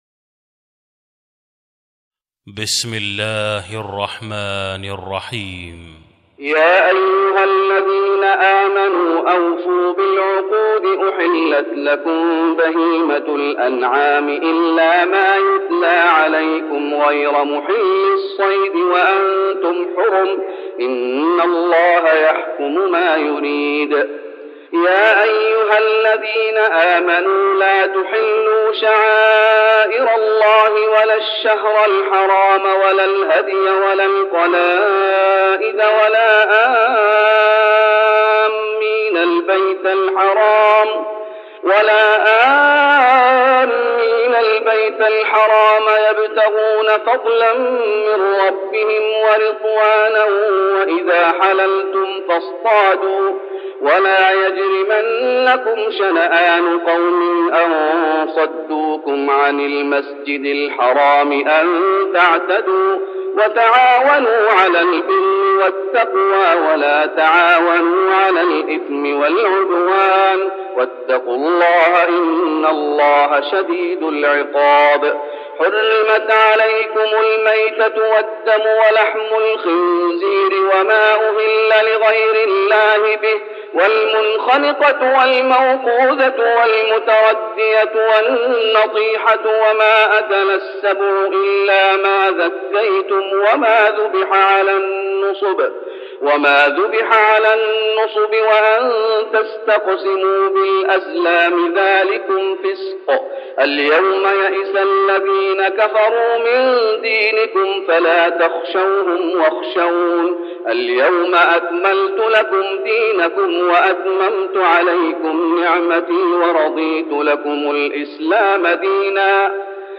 تهجد رمضان 1410هـ من سورة المائدة (1-26) Tahajjud Ramadan 1410H from Surah AlMa'idah > تراويح الشيخ محمد أيوب بالنبوي عام 1410 🕌 > التراويح - تلاوات الحرمين